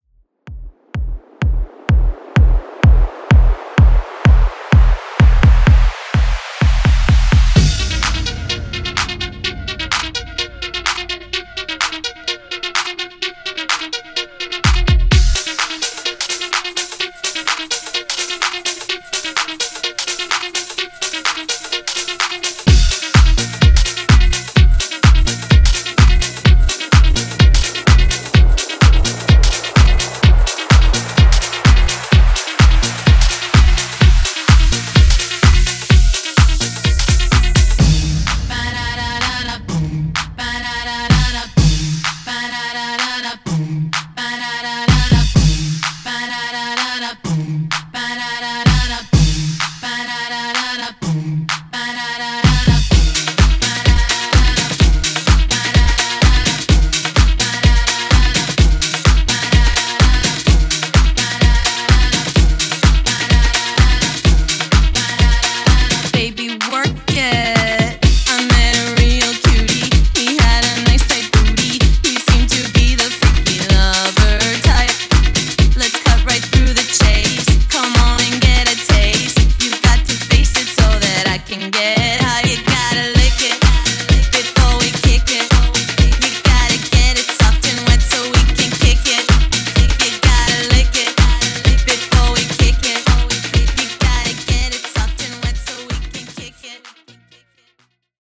Classic House)Date Added